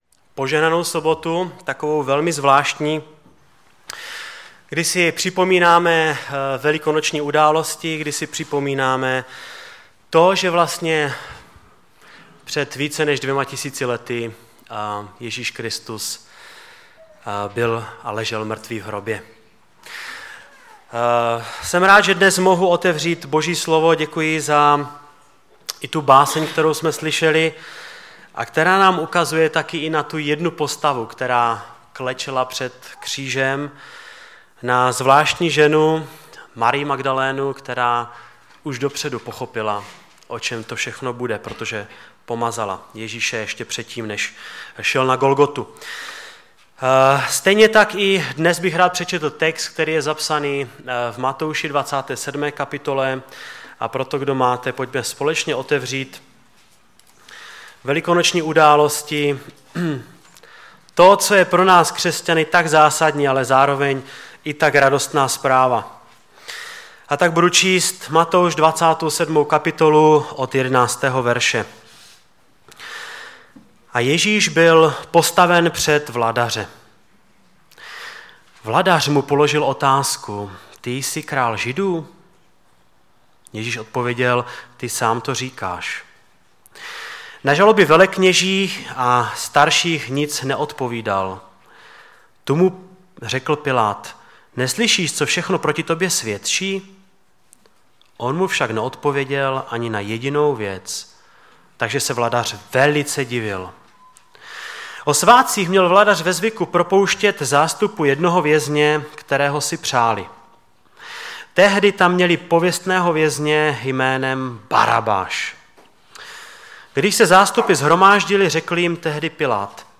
Kázání
ve sboře Ostrava-Radvanice v rámci Památky Večeře Páně.